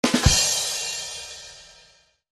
Звуки цимбалы
Завершающий мотив